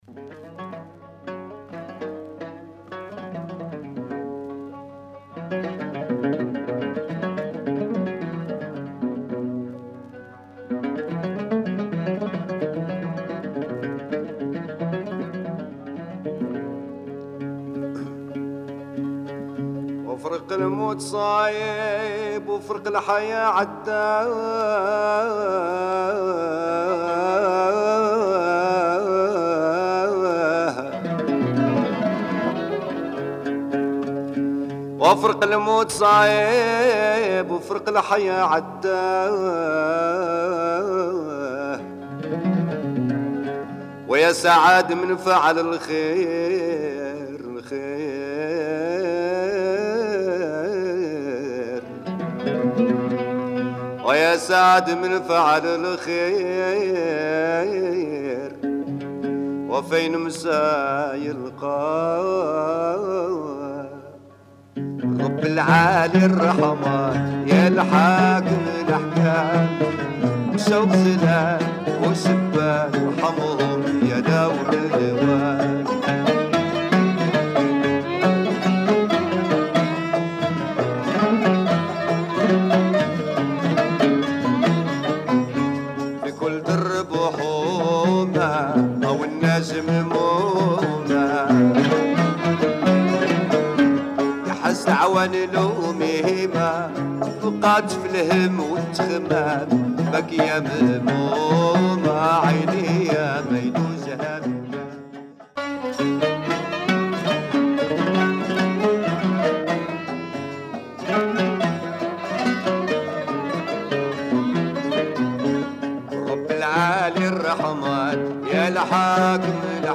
Moroccan song
oud